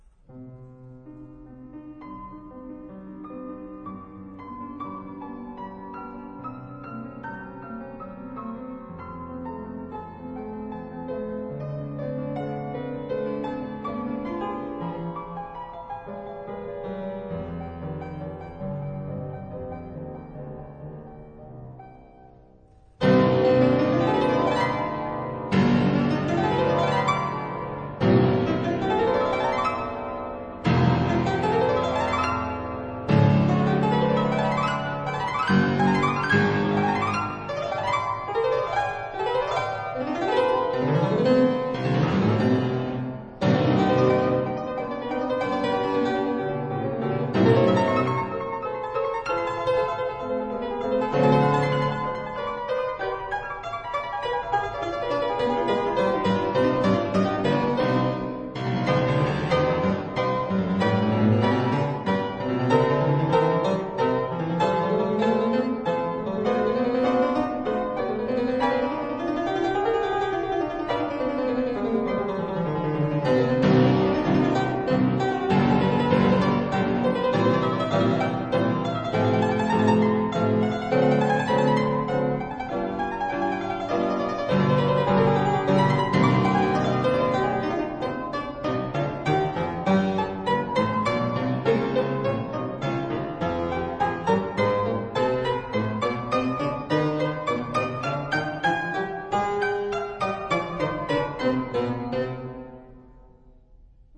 試聽三則是C小調的32個變奏曲，中間的一個變奏。